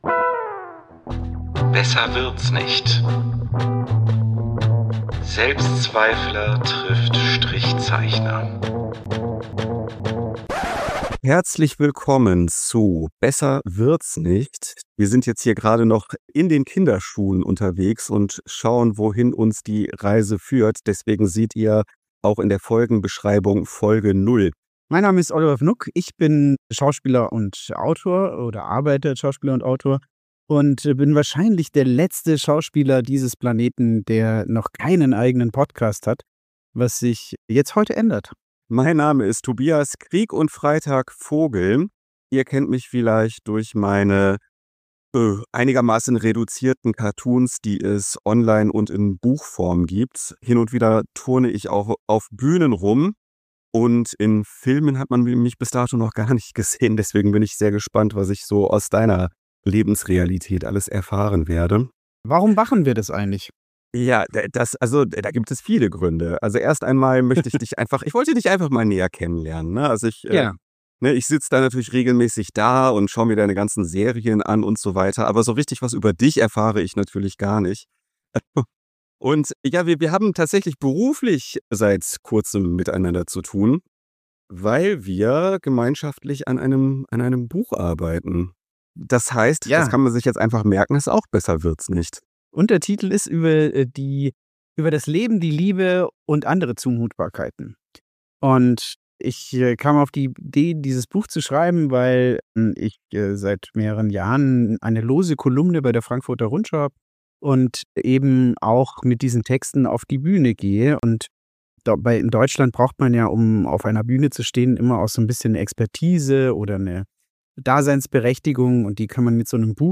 Beschreibung vor 5 Monaten Liebe Leute, aller Podcast-Anfang ist schwer, weshalb wir mit der Soundqualität nur so mittel zufrieden sind.